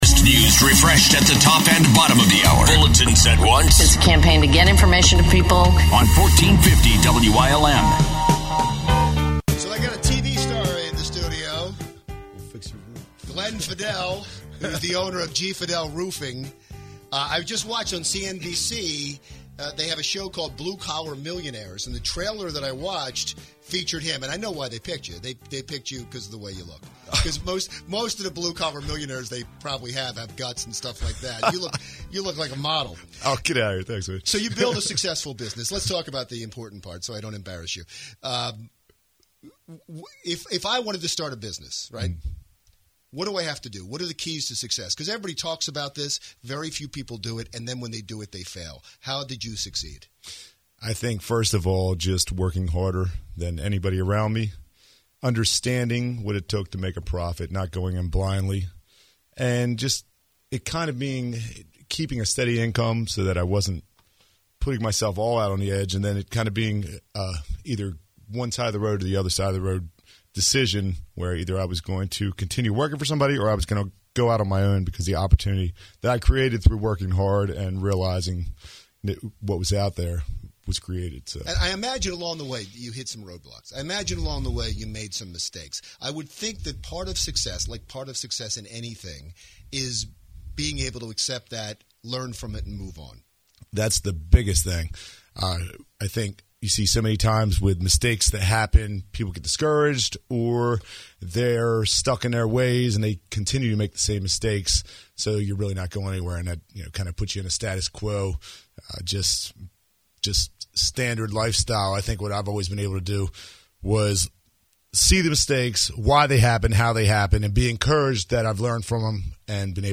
1450 Radio Interview